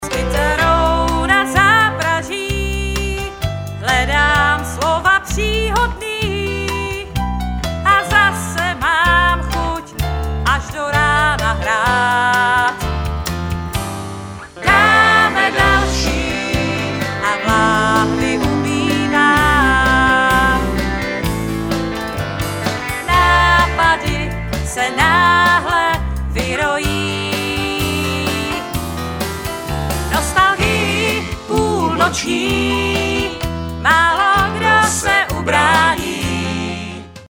Sólový zpěv